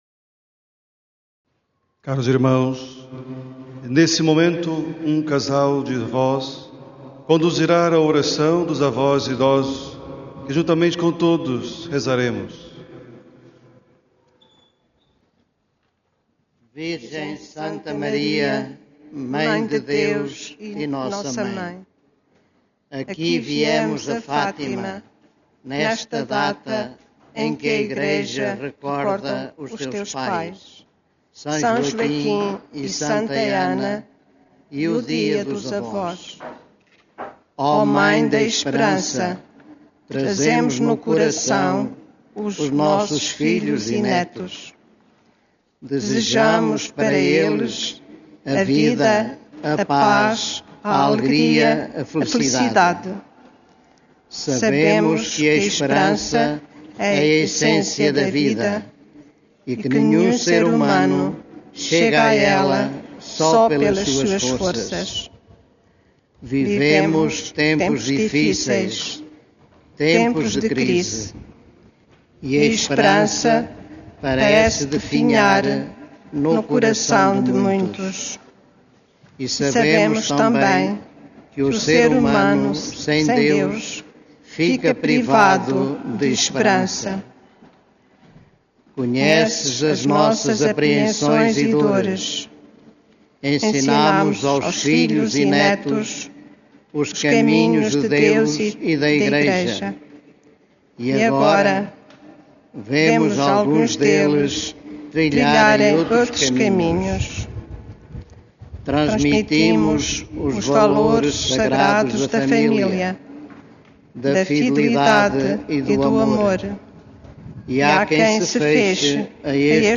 Após a homilia, um casal de idosos leu a oração dos avós.
Áudio da leitura da oração dos avós
oracaodosavos.mp3